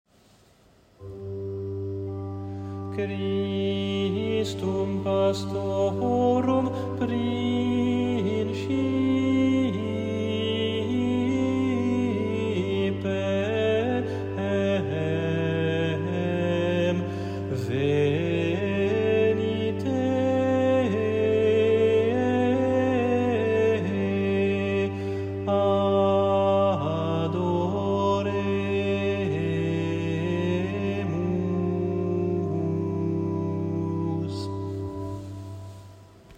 Antienne invitatoire : Christum, pastorum [partition LT]